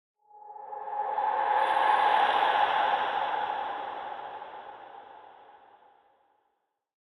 Minecraft Version Minecraft Version latest Latest Release | Latest Snapshot latest / assets / minecraft / sounds / ambient / nether / soulsand_valley / mood3.ogg Compare With Compare With Latest Release | Latest Snapshot